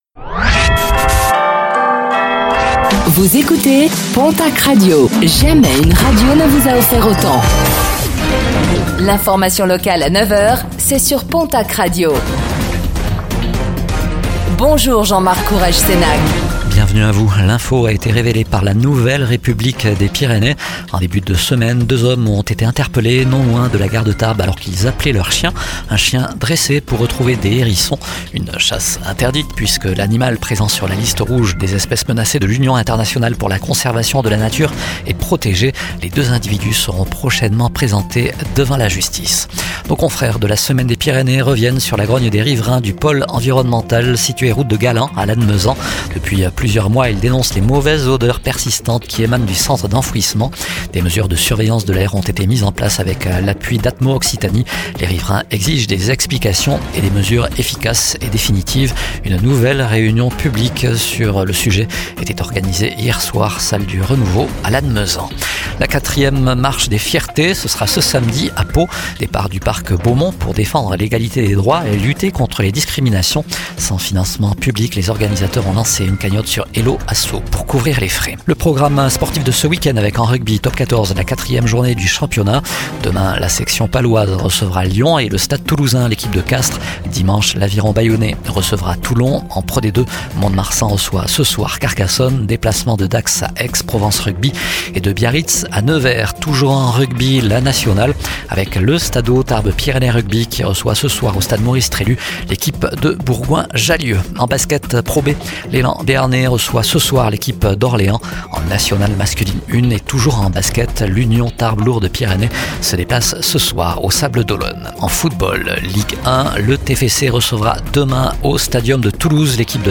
Réécoutez le flash d'information locale de ce vendredi 26 septembre 2025